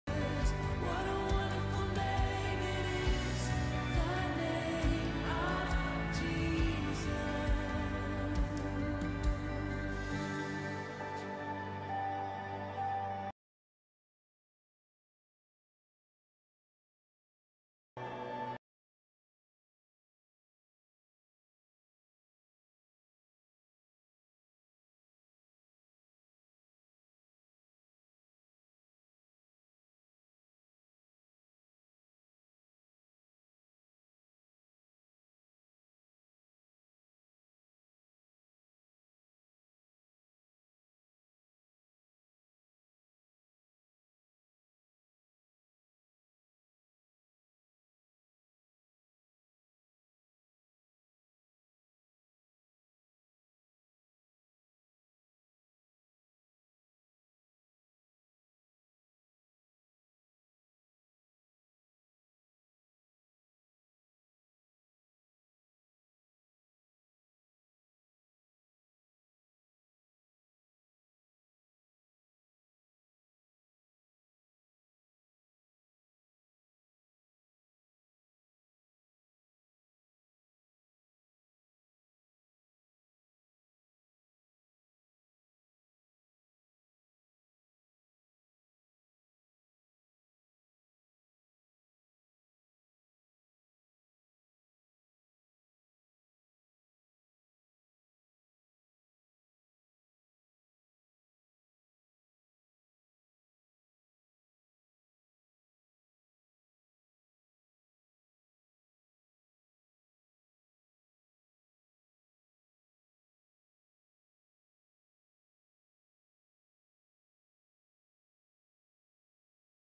April 18, 2021 (Morning Worship)